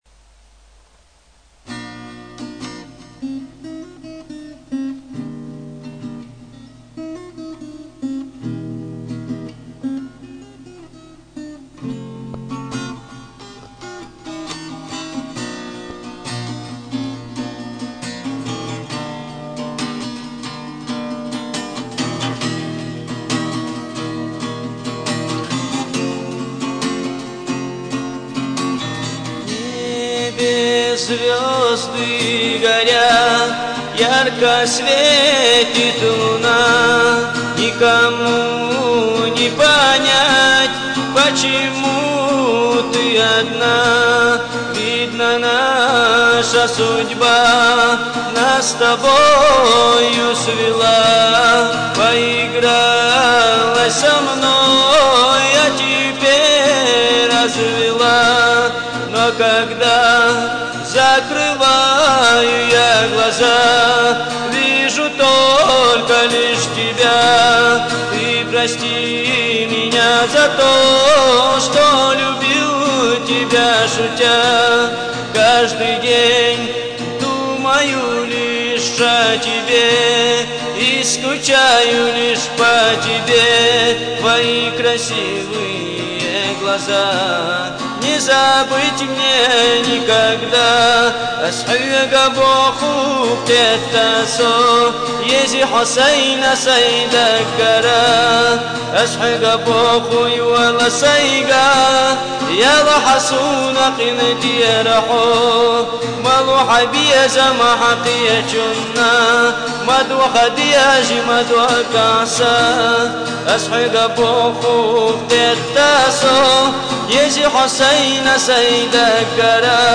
Лирические под гитару